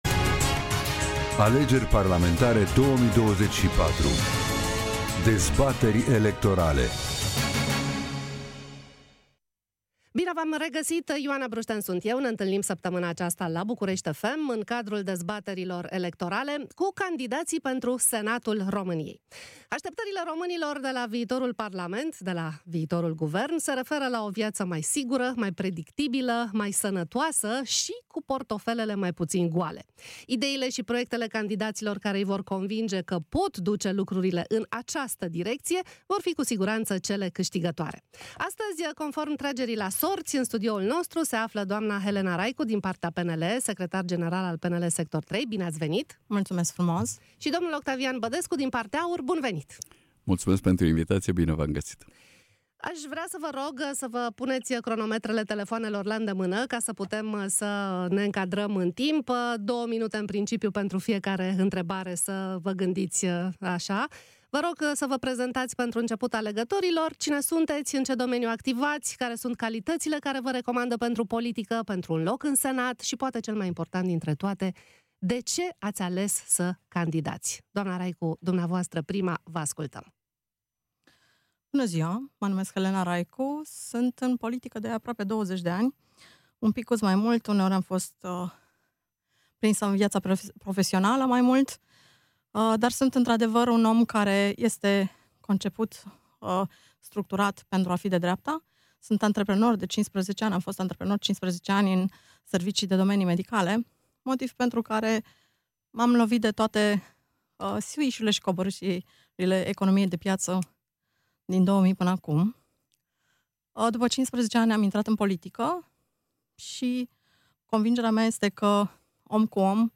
dezbatere electorala